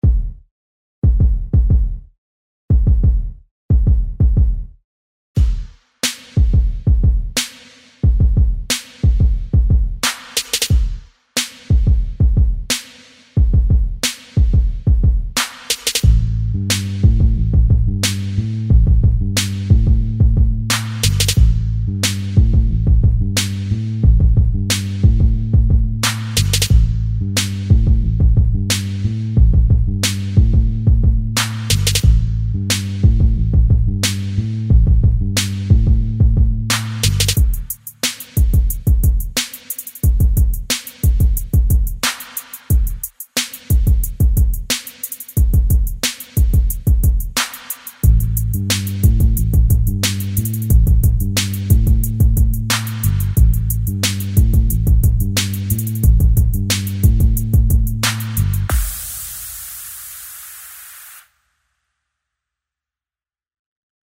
Y el plato fuerte está reservado para la segunda parte del proyecto, pues les he creado una sencilla base de hip-hop en la que grabarán unas rimas que escriban en grupo.